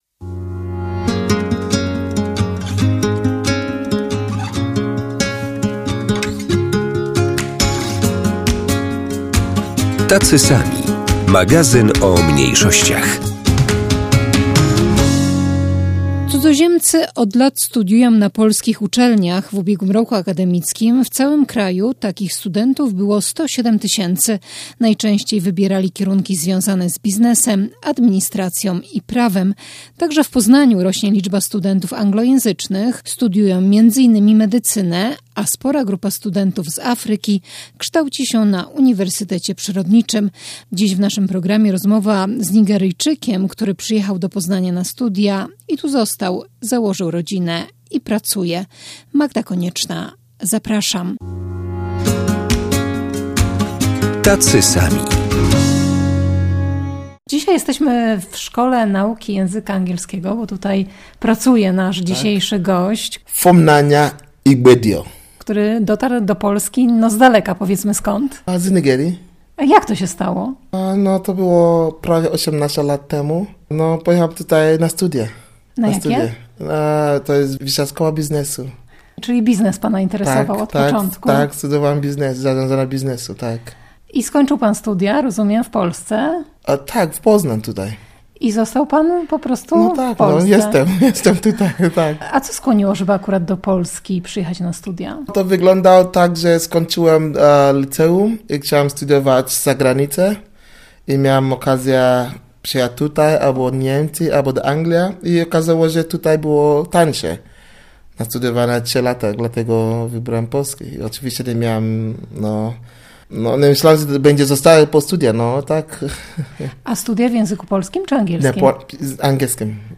Dziś w naszym programie rozmowa z Nigeryjczykiem, który przyjechał do Poznania na studia i tu został, założył rodzinę i pracuje.